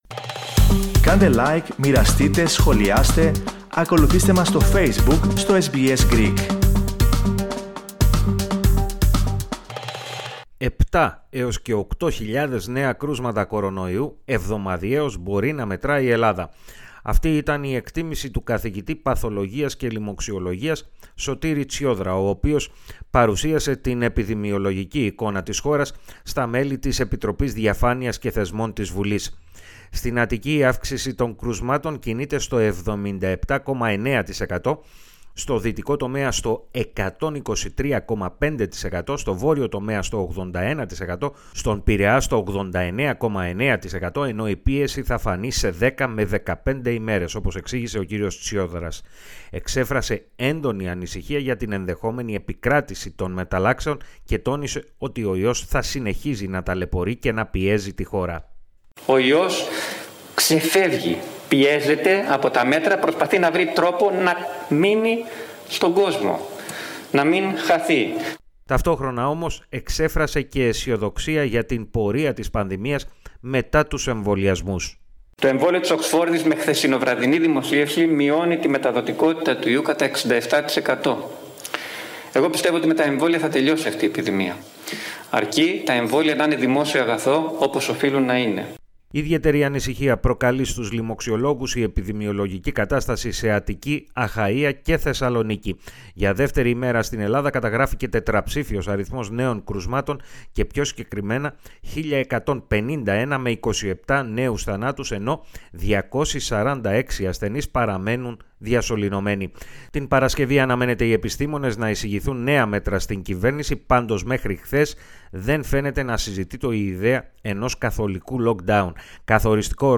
Ιδιαίτερα δυσοίωνες ήταν οι προβλέψεις του καθηγητή Λοιμωξιολογίας, Σωτήρη Τσιόδρα, για την αύξηση των κρουσμάτων κορωνοϊού, στην Ελλάδα, εφόσον συνεχιστεί η υφιστάμενη κατάσταση. Περισσότερα, ακούστε στην αναφορά